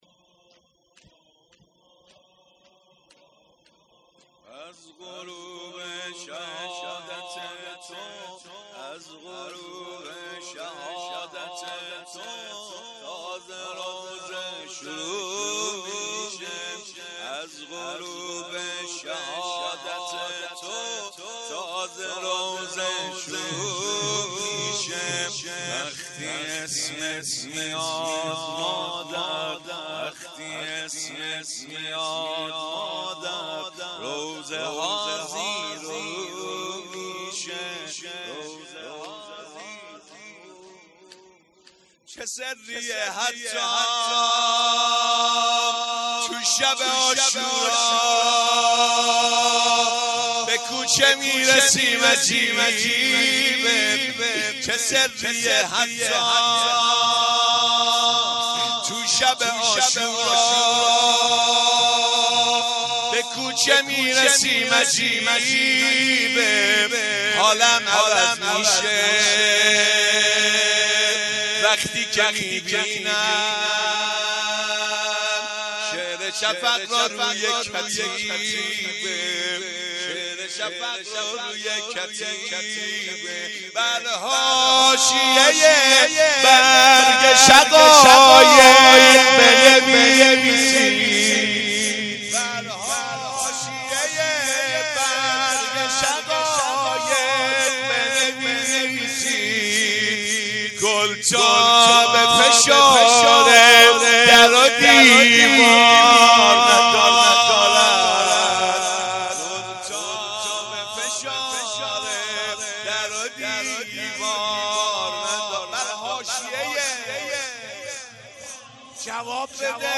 شب اول مراسم شهادت حضرت فاطمه زهرا سلام الله علیها آبان ۱۴۰۳